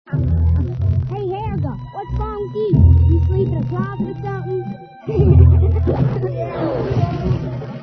Seeing that hardly anyone else seems to be offering up sounds from Hey Arnold!, I've decided to roll my own (it helps that I have a laptop, an audio cable, a stereo attached to the digibox, and thus the means to connect the three to record audio from eps of the show).